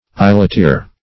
Search Result for " eyeleteer" : The Collaborative International Dictionary of English v.0.48: Eyeleteer \Eye`let*eer"\, n. A small, sharp-pointed instrument used in piercing eyelet holes; a stiletto.